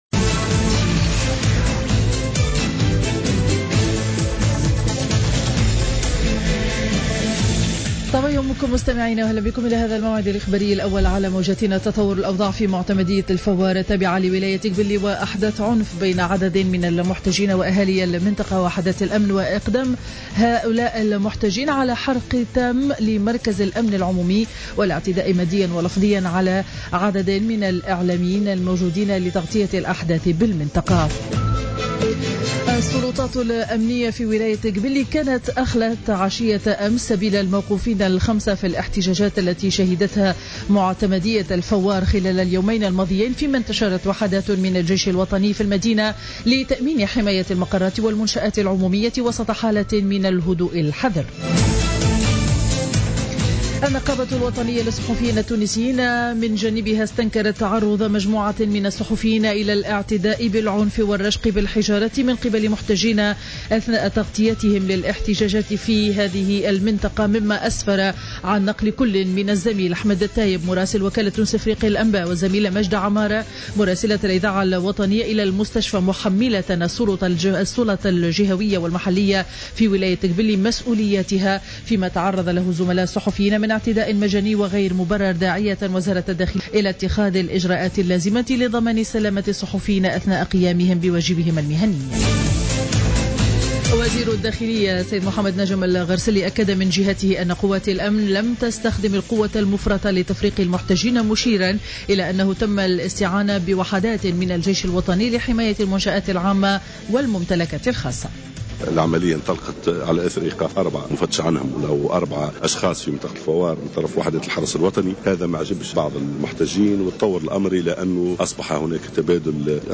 نشرة أخبار السابعة صباحا ليوم الأحد 10 ماي 2015